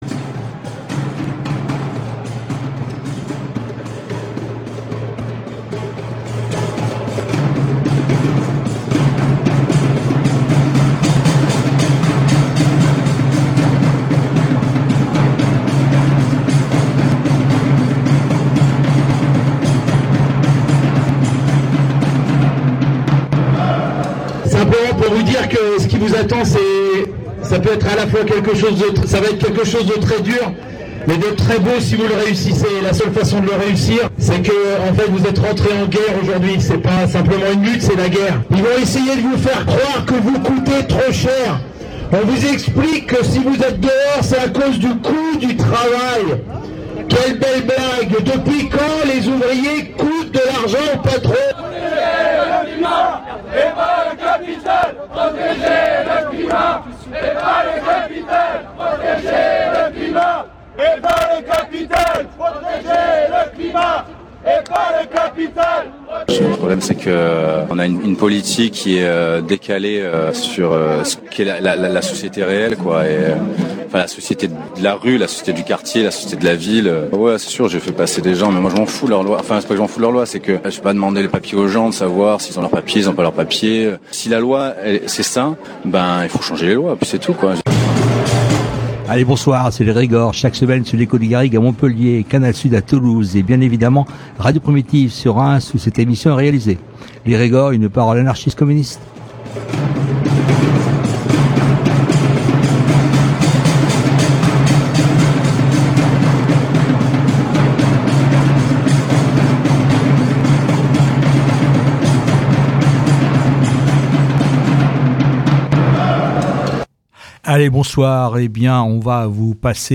Dans un premier temps, nous serons à la réunion publique antinucléaire organisée par le collectif Arrêt Du Nucléaire le 4 octobre à Paris avec cette question : LE NUCLÉAIRE VA-t-IL SAUVER LE CLIMAT ? et par la suite, nous entendrons le reportage réalisé à Rouen où se tenait la Manifestation antinucléaire des 11 et 12 octobre 2024.